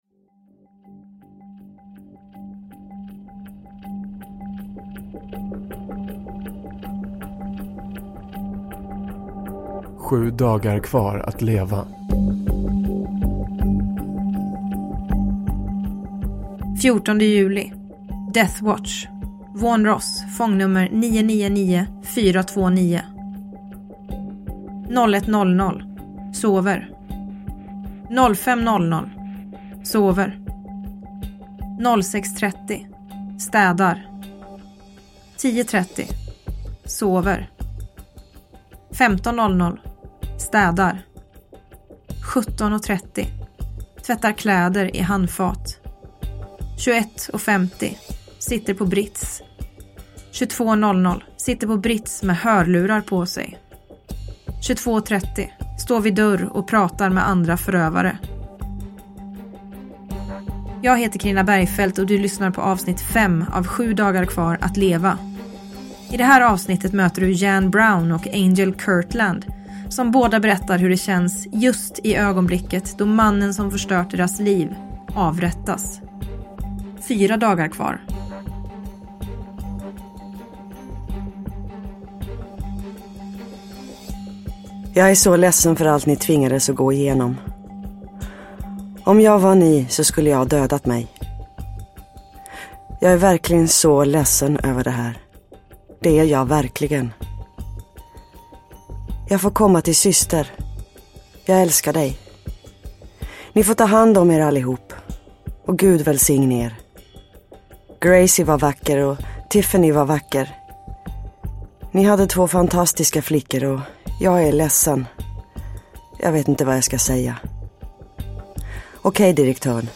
Bergfeldts Amerika. S1A5, Sju dagar kvar att leva – Ljudbok – Laddas ner